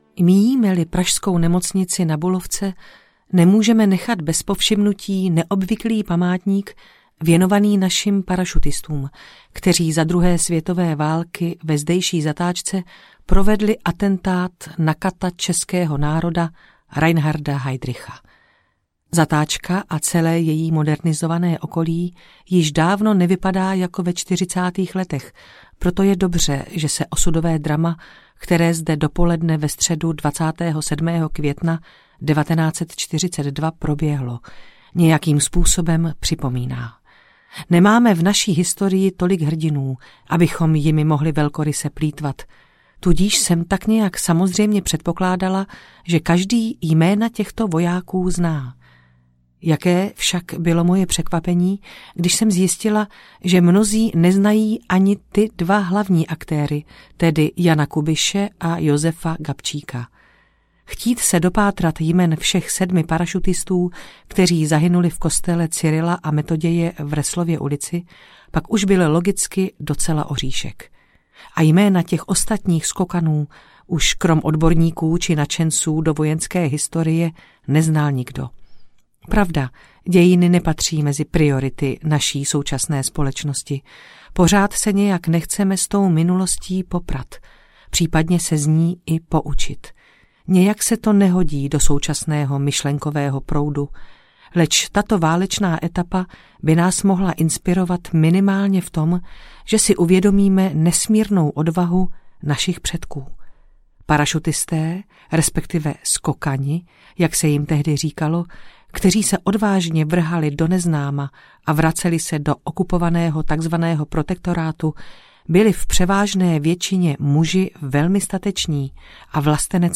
Doskočiště Protektorát audiokniha
Ukázka z knihy
doskociste-protektorat-audiokniha